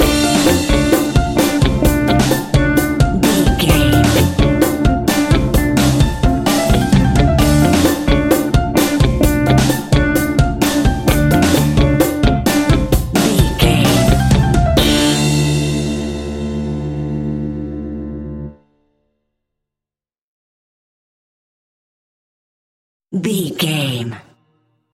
Aeolian/Minor
maracas
percussion spanish guitar
latin guitar